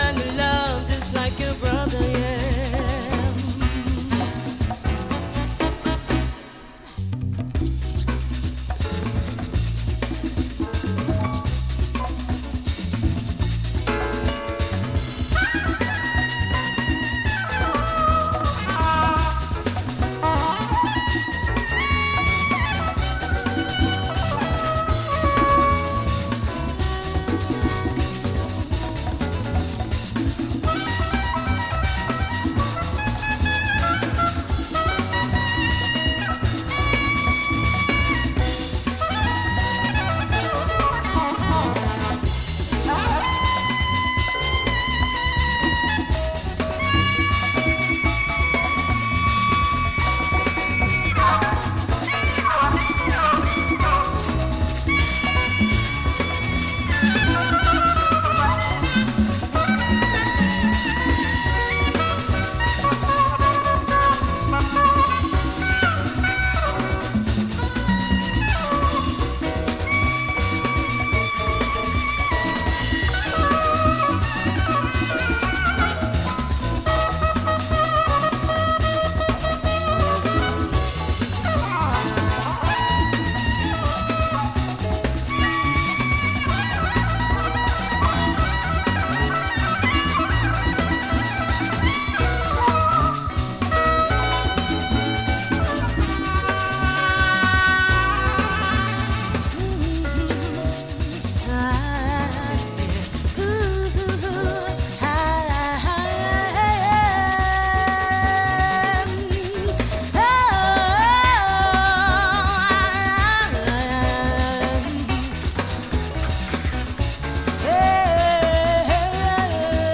Rare original funk with afro touch from 1975.